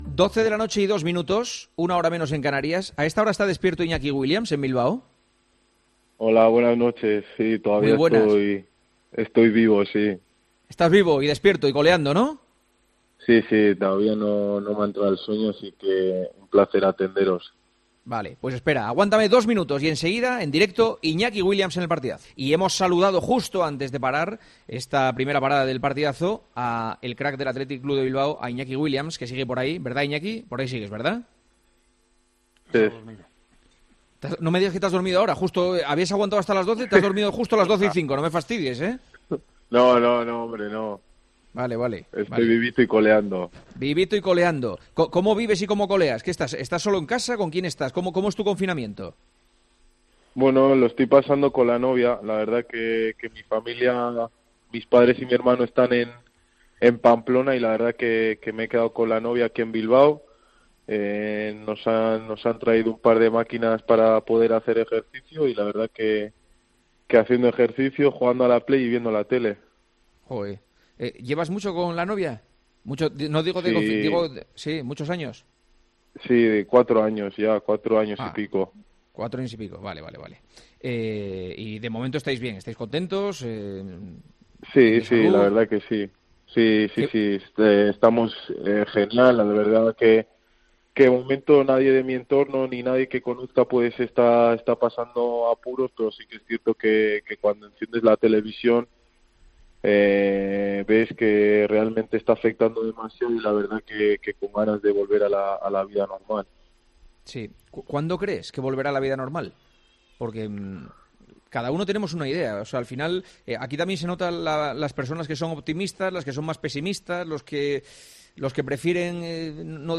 ¿Cómo vive el confinamiento un futbolista de LaLiga? Hablamos con el delantero del Athletic Club, en El Partidazo de COPE.